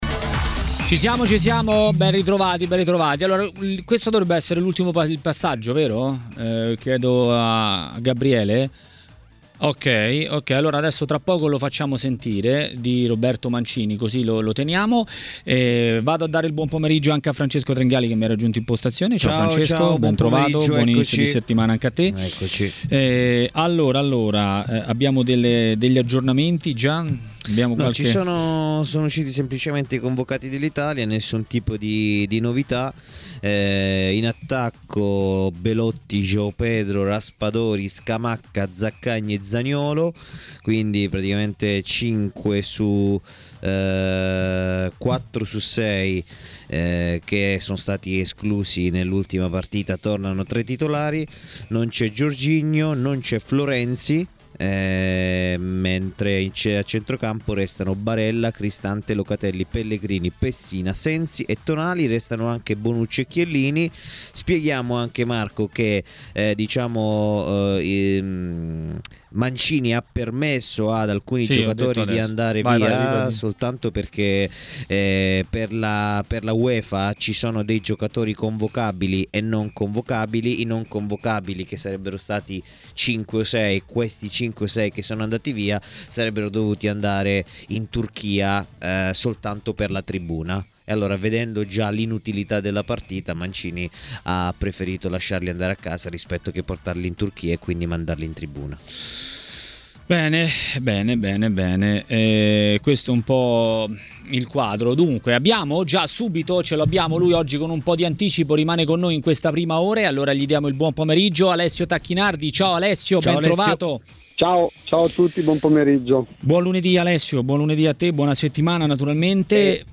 Durante la trasmissione Maracanà a TMW radio è intervenuto Alessio Tacchinardi, tecnico ed ex calciatore, per parlare di vari temi.